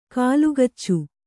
♪ kālugaccu